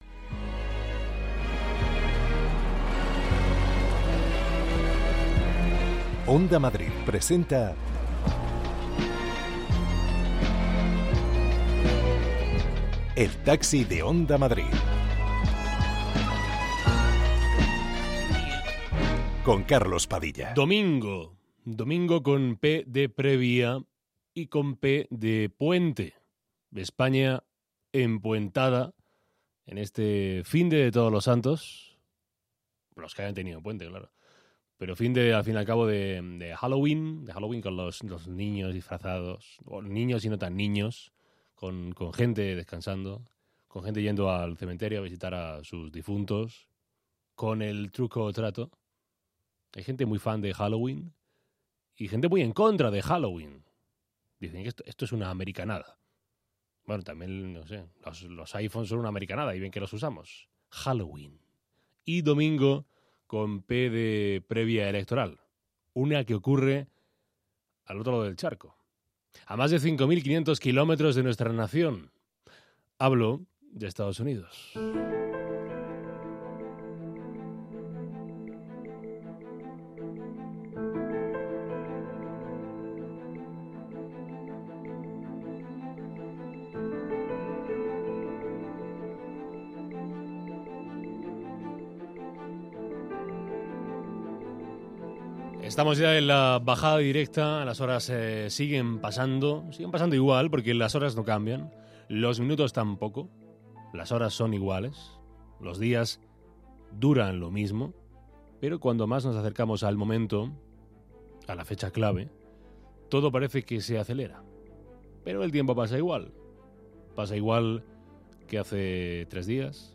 Conversaciones para escapar del ruido. Recorremos Madrid con los viajeros más diversos del mundo cultural, político, social, periodístico de España...